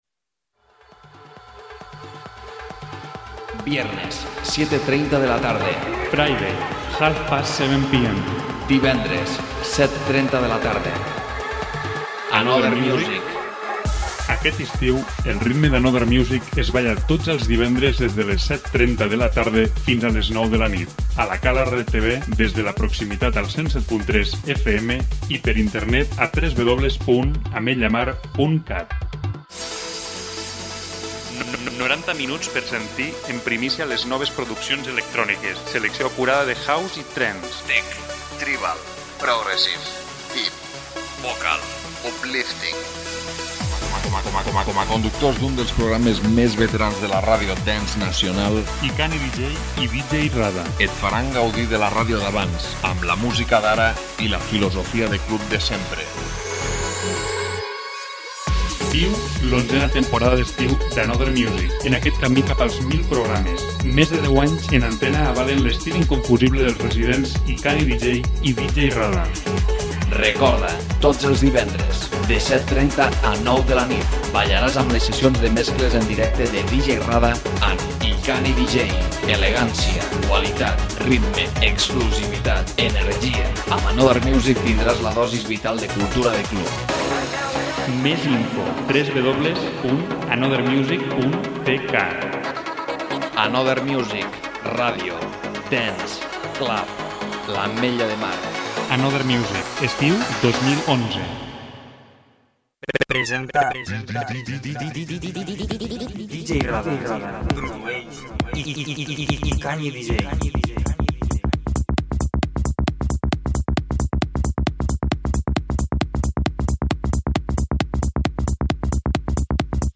presentació de nous tracks Trance i House